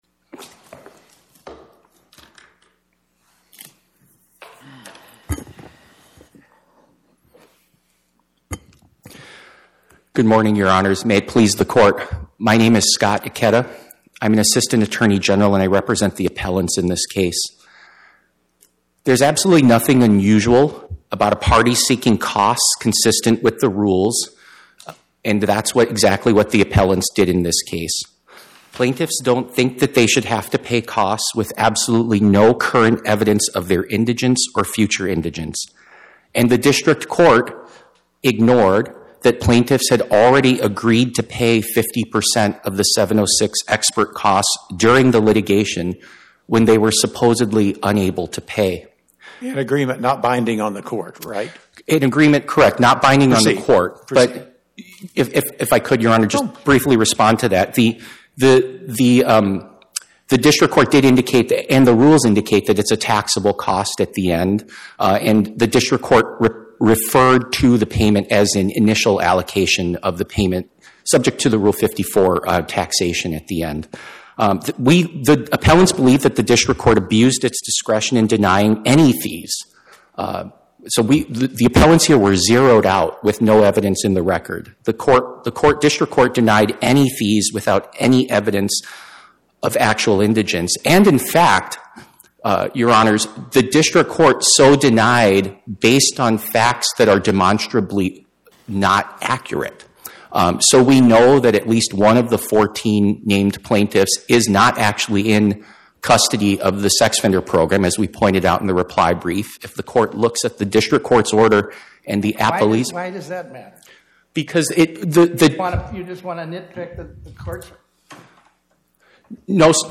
Oral argument argued before the Eighth Circuit U.S. Court of Appeals on or about 10/21/2025